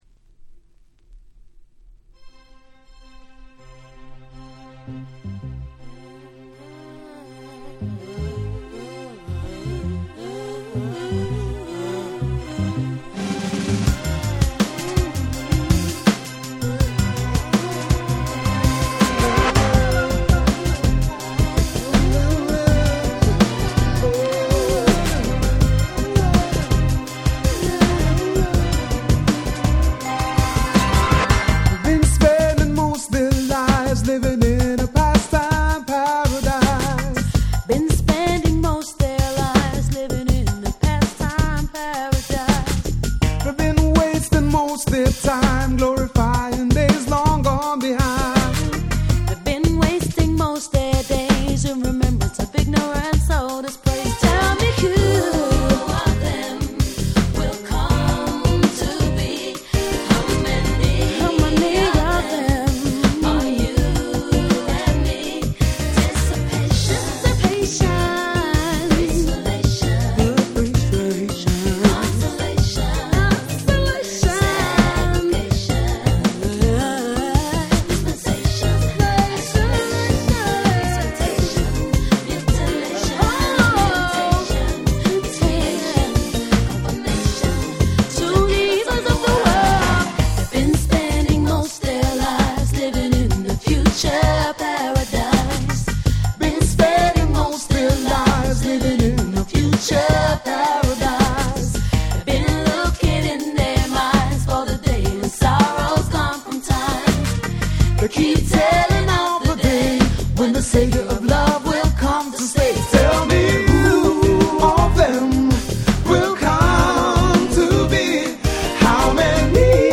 92' Nice UK R&B LP !!